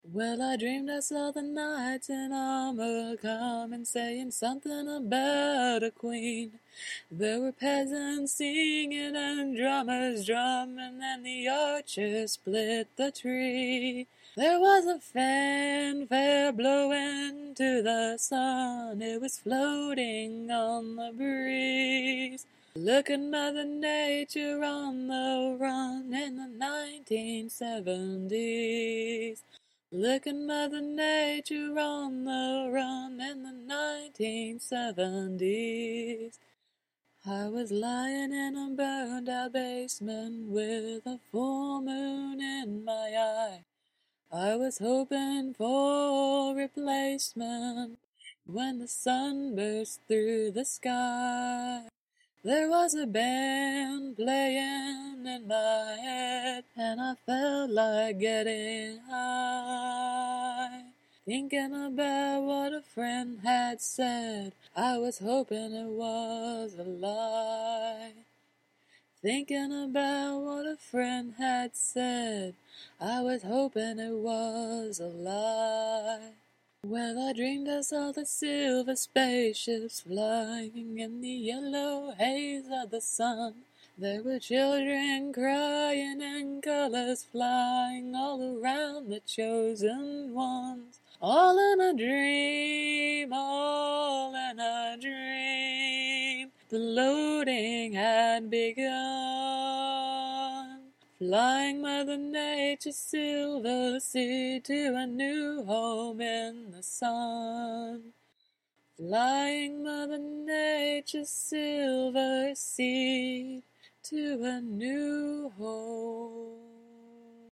KARAOKE GO.